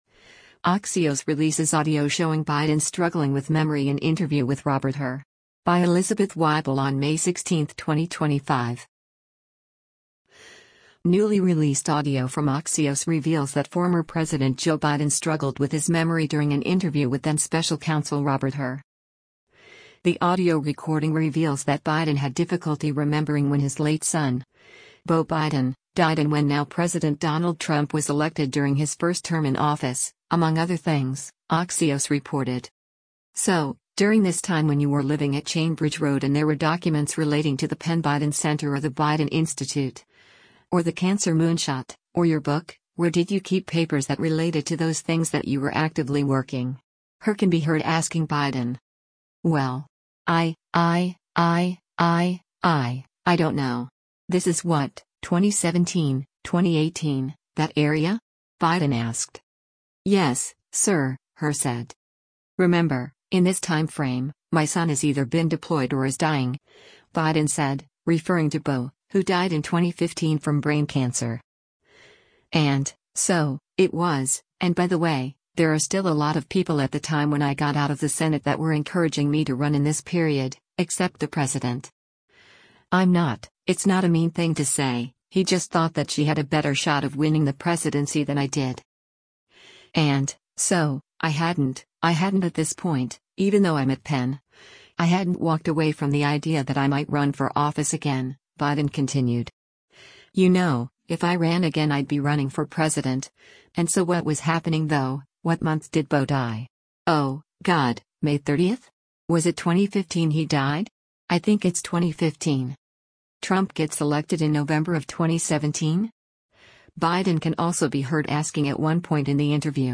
Axios Releases Audio of Biden Struggling with Memory in Interview with Robert Hur
Newly released audio from Axios reveals that former President Joe Biden struggled with his memory during an interview with then-special counsel Robert Hur.
“Trump gets elected in November of 2017?” Biden can also be heard asking at one point in the interview.